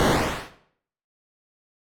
parry.wav